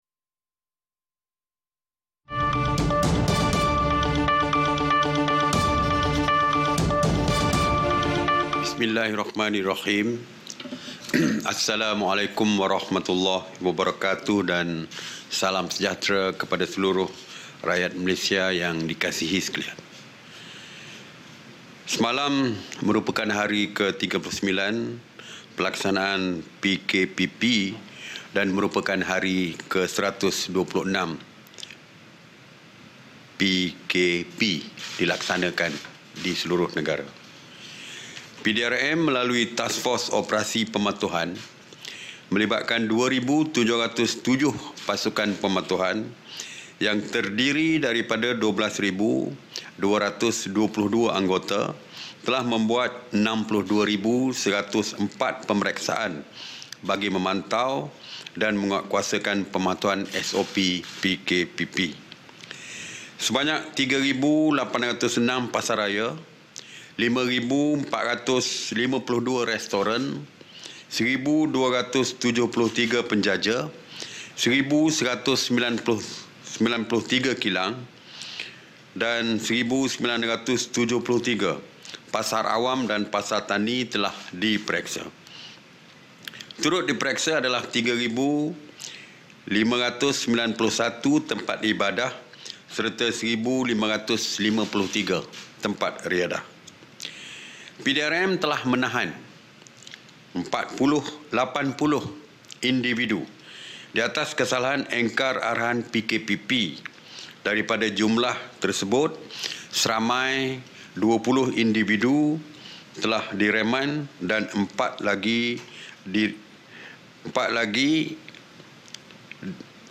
[SIDANG MEDIA] Perintah Kawalan Pergerakan Pemulihan (PKPP)
Ikuti sidang media khas berhubung Perintah Kawalan Pergerakan Pemulihan (PKPP), Menteri Kanan Pertahanan, Datuk Seri Ismail Sabri Yaakob.